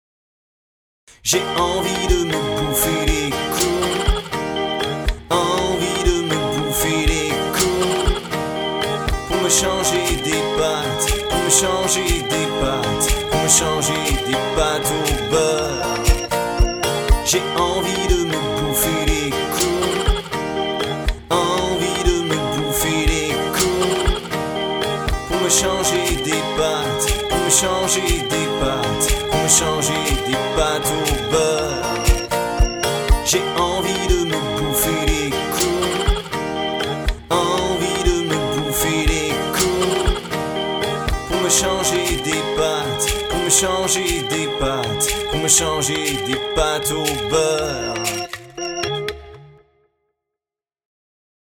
Le Jingle ! Le Jingle!
Les prises vocales bientôt en boite au STUDIO PUTOX UNIVERSAL!